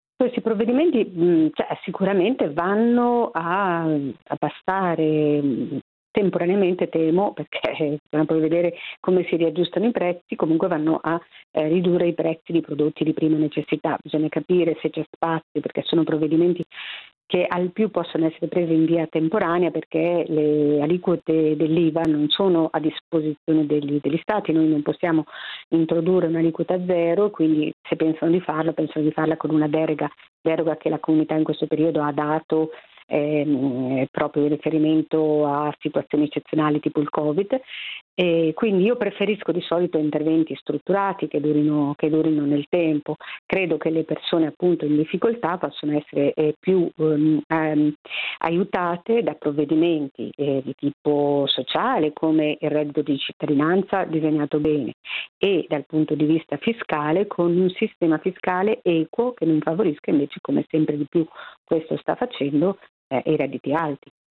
Sentiamo ancora Cecilia Maria Guerra